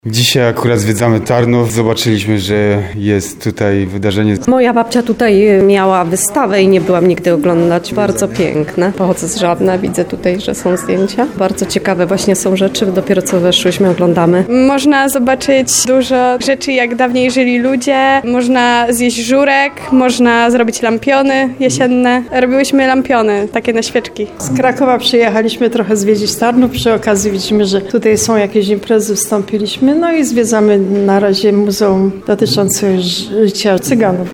Warsztaty pszczelarskie i garncarskie, zwiedzanie ekspozycji i atrakcje dla całych rodzin przyciągnęły mieszkańców Tarnowa i przyjezdnych na dziedziniec Muzeum Etnograficznego